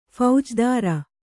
♪ phaujdāra